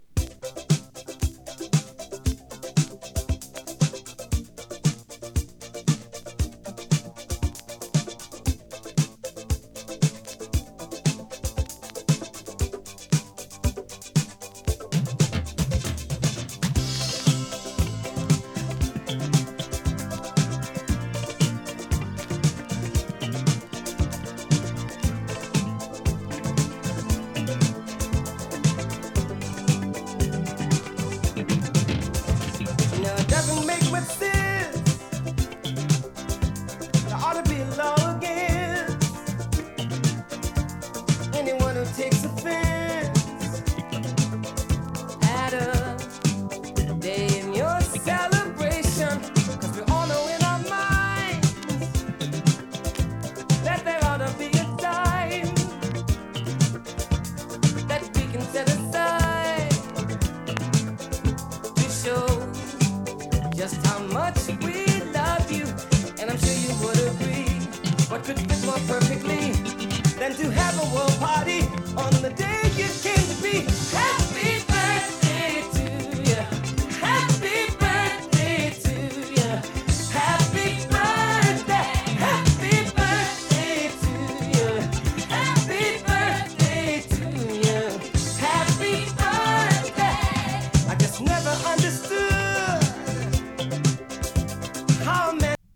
ディスコ ソウル
誕生日ソングの大定番！
[2track 12inch]＊A面に稀に極軽いパチ・ノイズ。